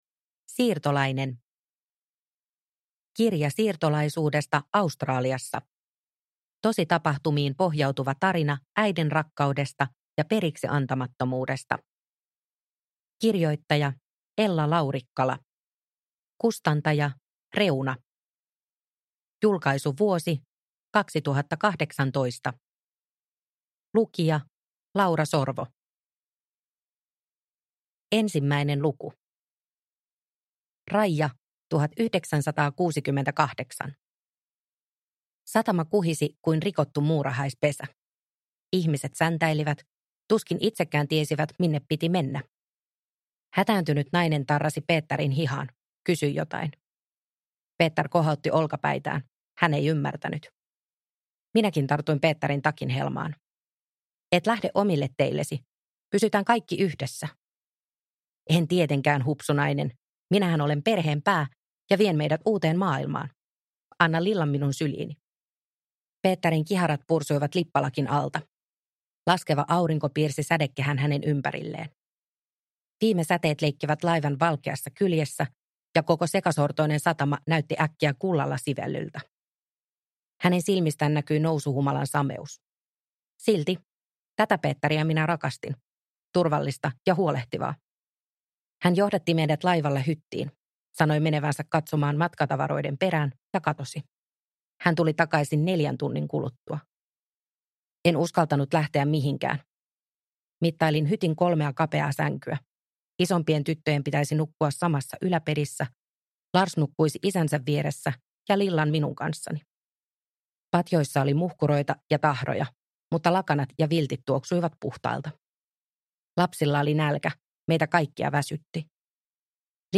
Siirtolainen – Ljudbok – Laddas ner